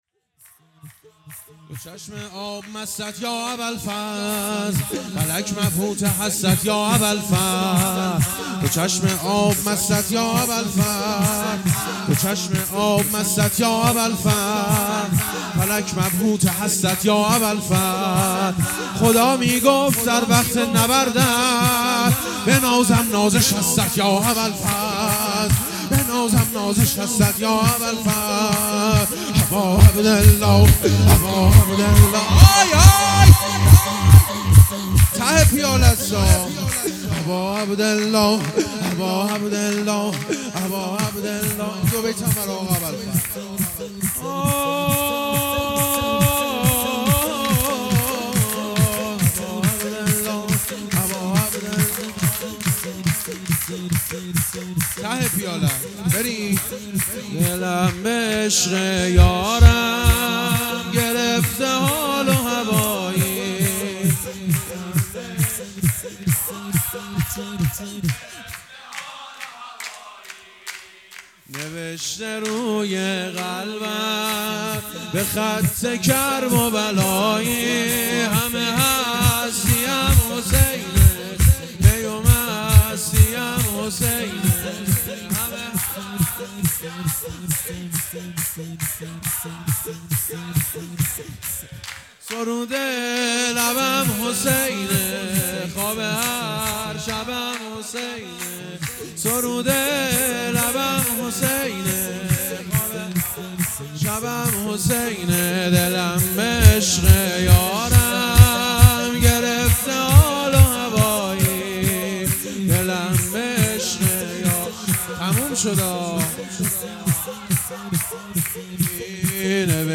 شور | دوچشم آب مستت یا اباالفضل(ع)
میلاد سرداران کربلا، هیأت‌ثامن‌الحجج‌(ع)اصفهان، ماه‌شعبان |چهارشنبه١٨اسفند١۴٠٠